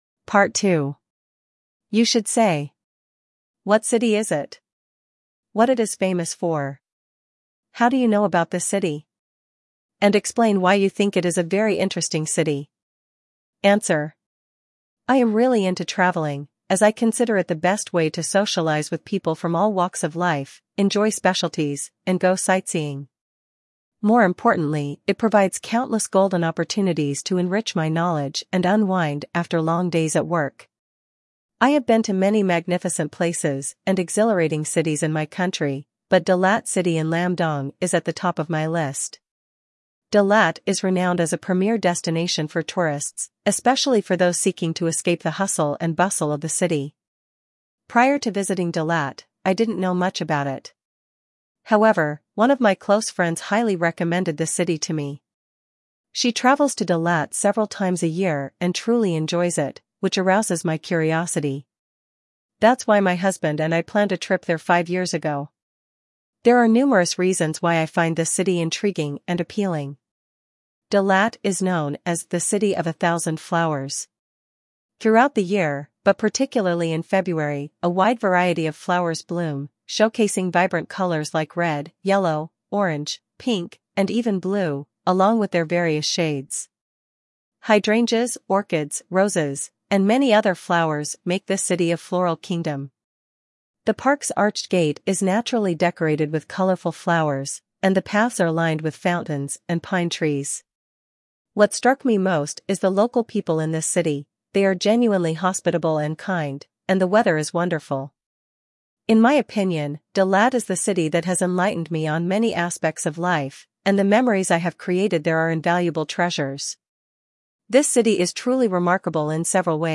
Jenny (English US)